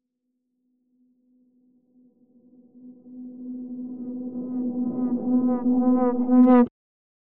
AV_Distorted_Reverse_FX.wav